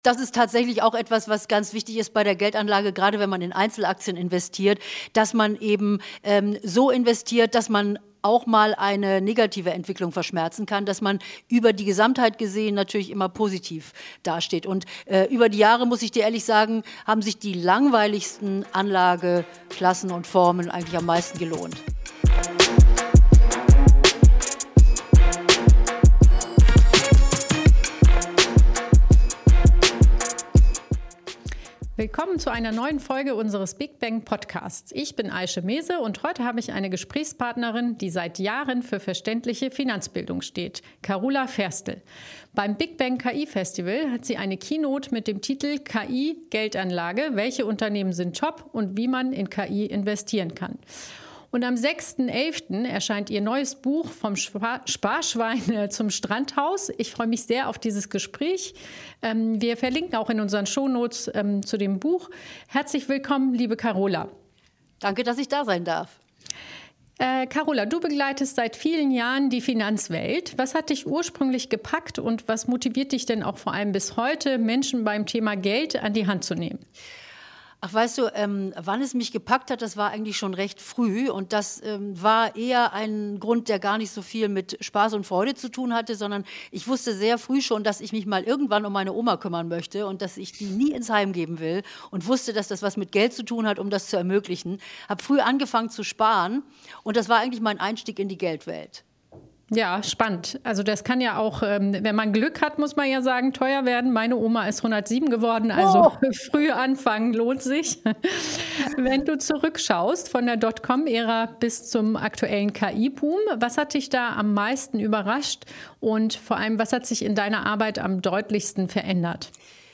In dieser Folge spricht Host